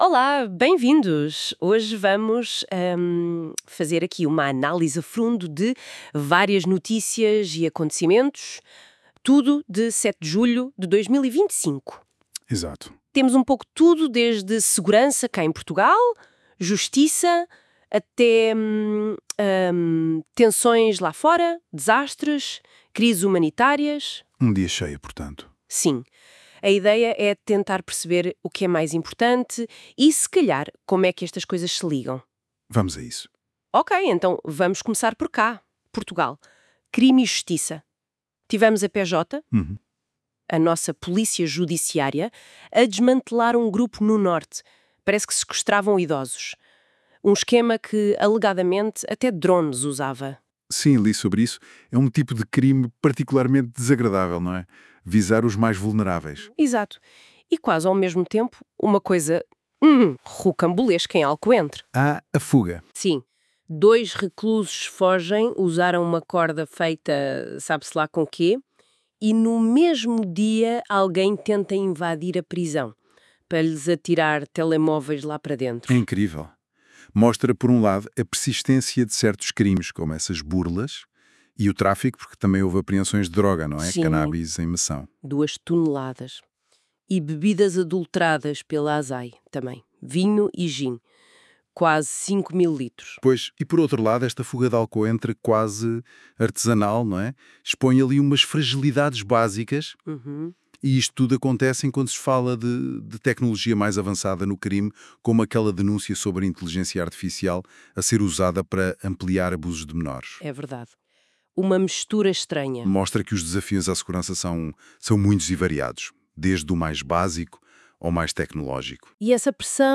Resumo áudio